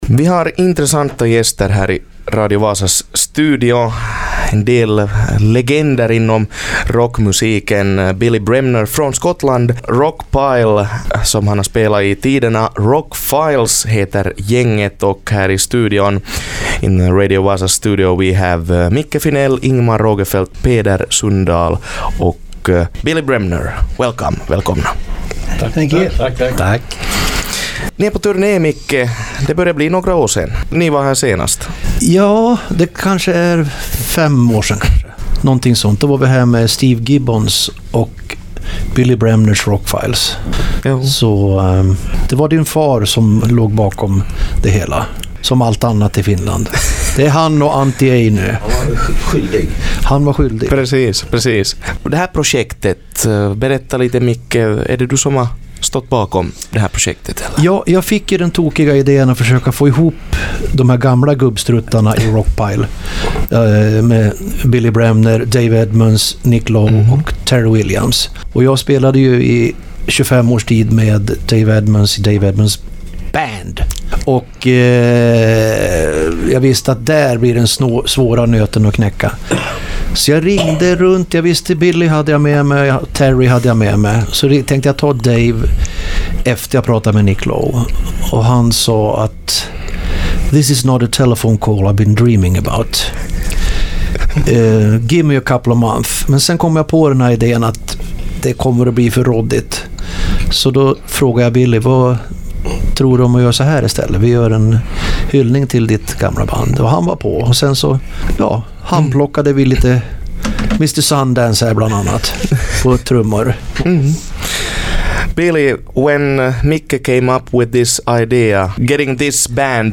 Rockfiles spelar äkta ”ränttätänttä” rock n roll vid Irish Pub O’Malley’s Vaasa den 8.3 med Billy Bremner känd från Rockpile with Dave Edmunds! Radio Vasas studio gästades av Billy Bremner